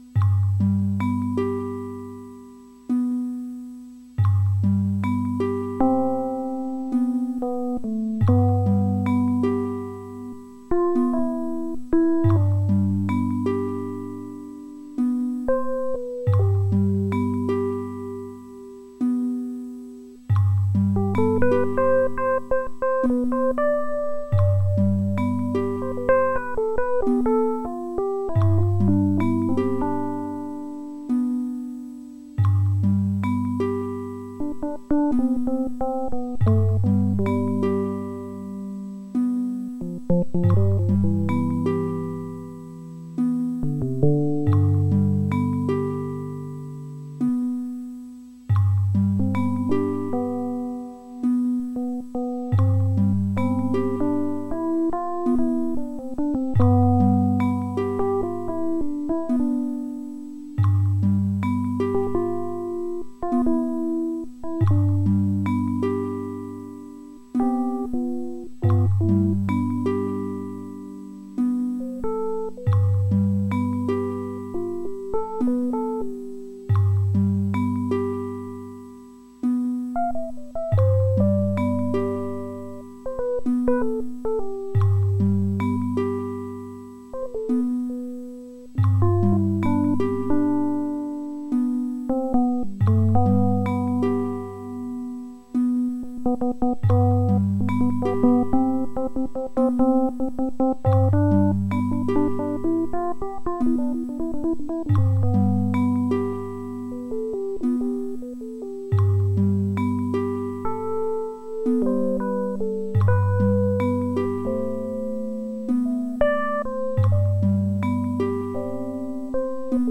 et une toute petite impro sur electroplankton qui tourne en boucle.
Sentiment de pl�nitude